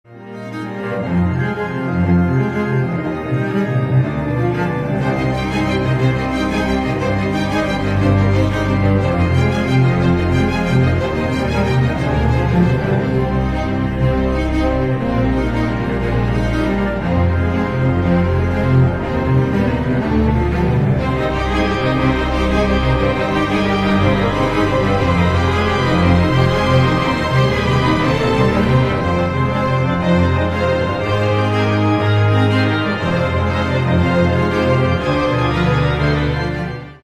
Рингтоны Без Слов